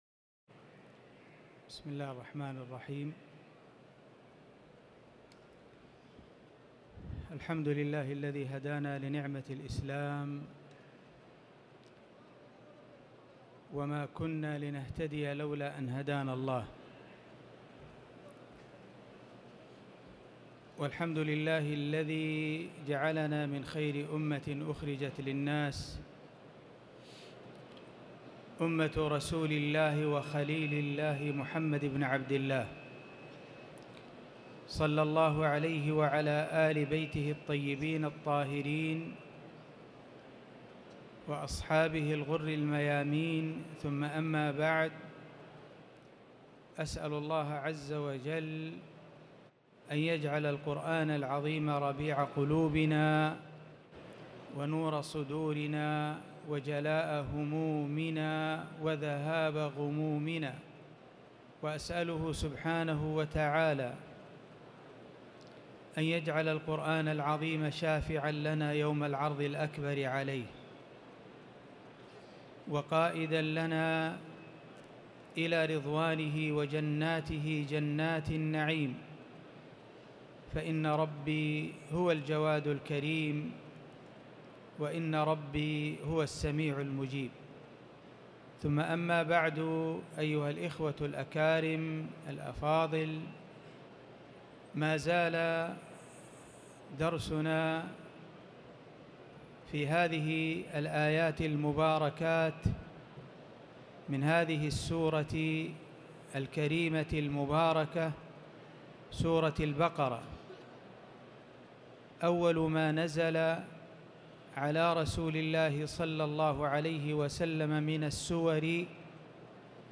تاريخ النشر ٢٢ جمادى الأولى ١٤٤٠ هـ المكان: المسجد الحرام الشيخ